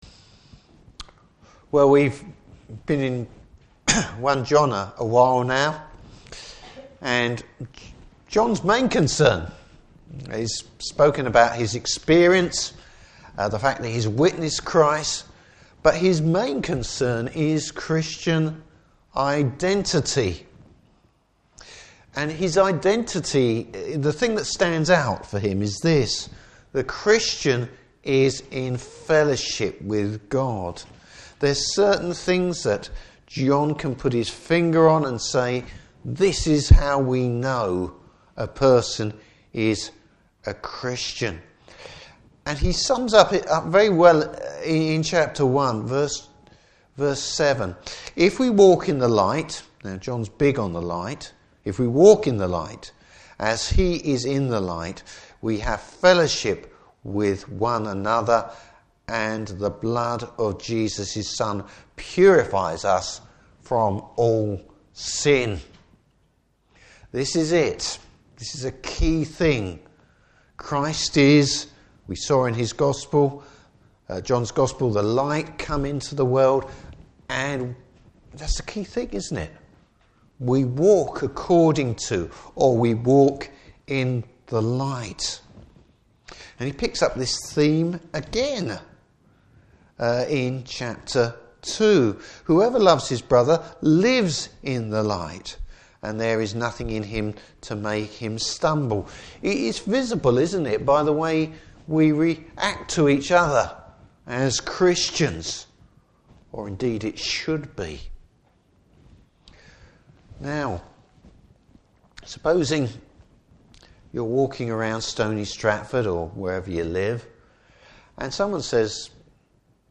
Service Type: Evening Service Bible Text: 1 John 2:15-29.